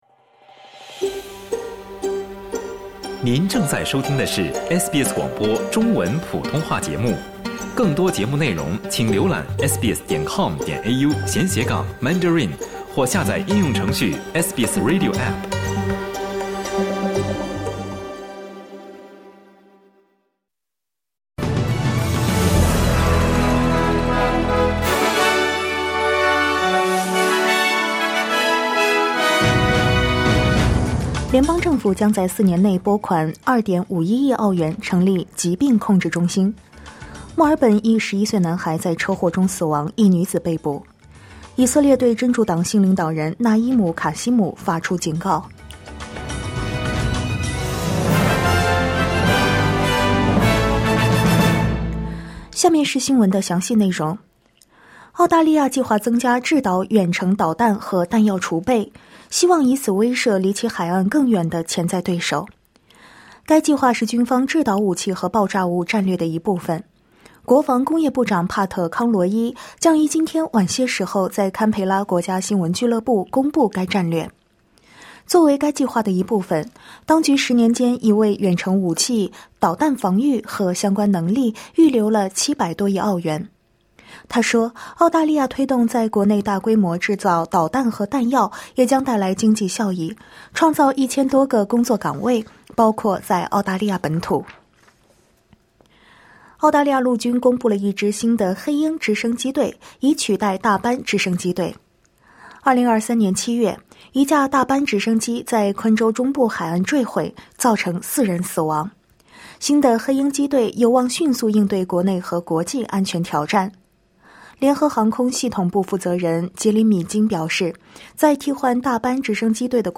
SBS早新闻（2024年10月30日）